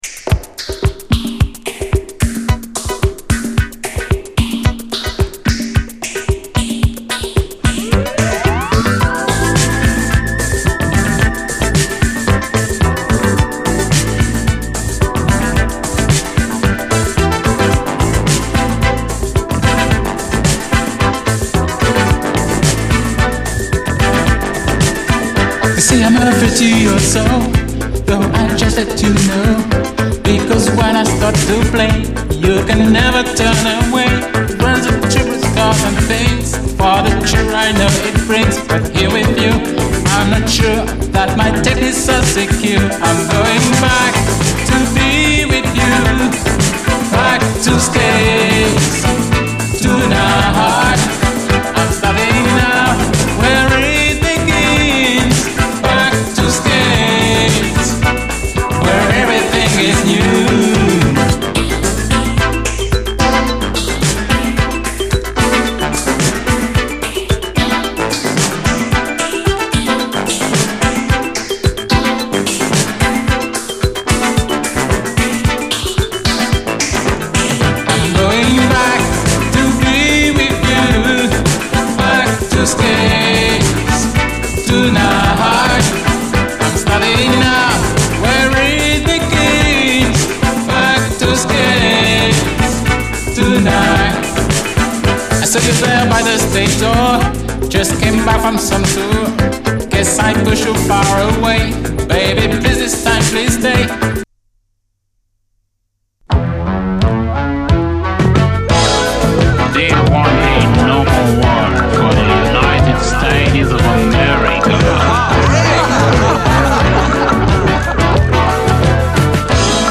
SOUL, 70's～ SOUL, DISCO
試聴ファイルはこの盤からの録音です